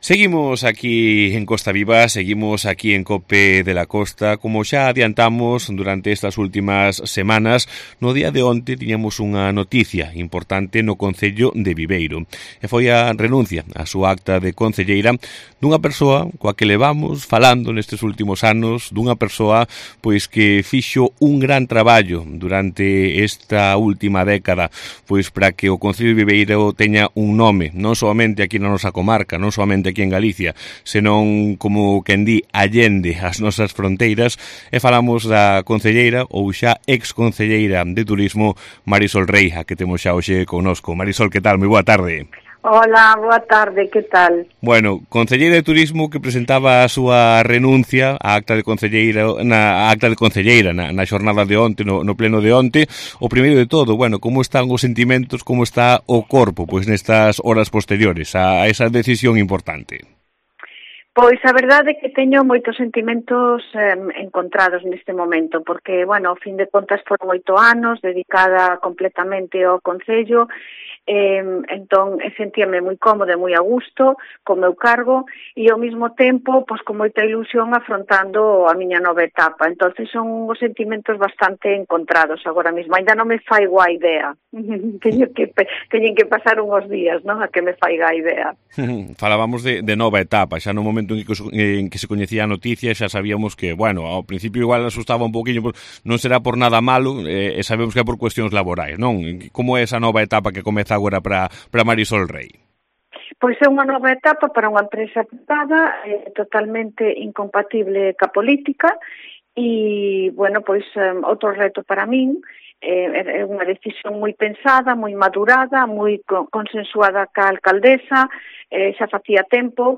La ya exconcejala de Turismo hizo balance en COPE de la Costa de los logros alcanzados durante casi una década de promoción y potenciación de los atractivos del municipio